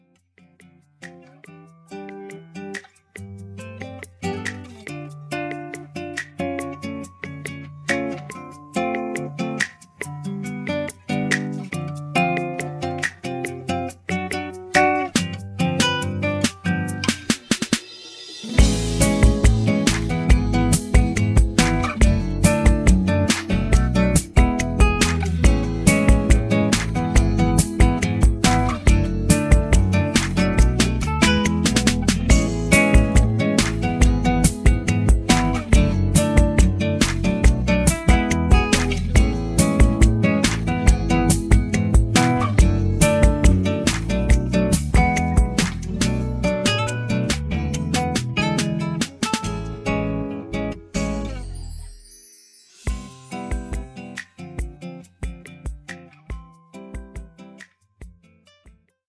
음정 -1키 3:39
장르 가요 구분 Voice Cut